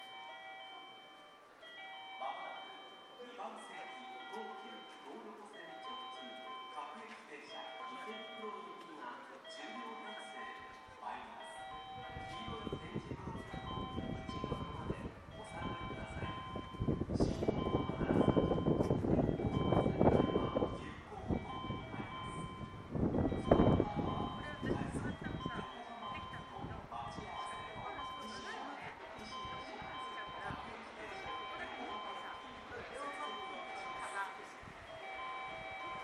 接近放送東急東横線直通　各駅停車　池袋行き接近放送です。